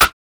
Snare (BadaBoom).wav